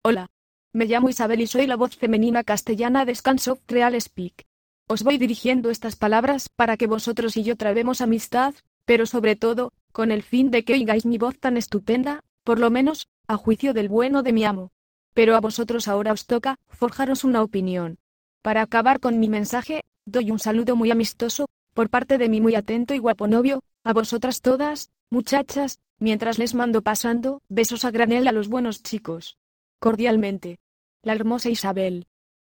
Texte De Démonstration Lu Par Isabel (Nuance RealSpeak; distribué sur le site de Nextup Technology; Femme; espagnool)